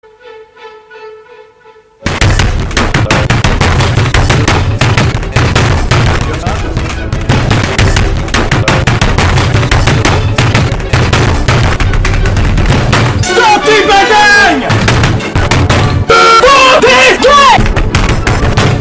Play, download and share xqcl hitting his desk original sound button!!!!
xqcl-hitting-his-desk.mp3